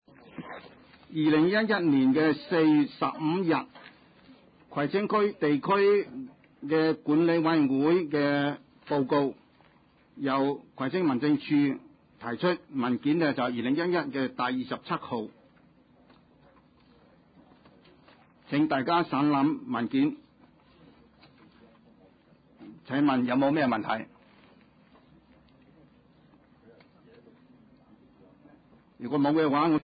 葵青區議會第七十一次會議